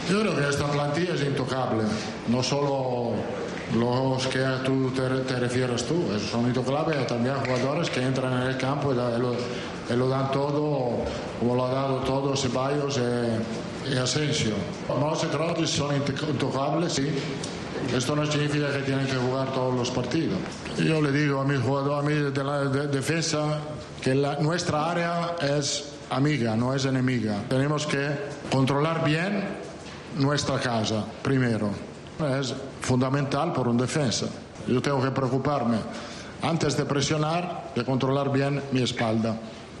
Real Madrid | Rueda de prensa